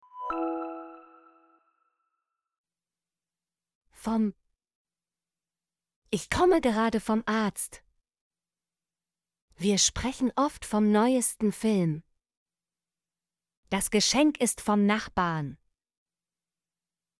vom - Example Sentences & Pronunciation, German Frequency List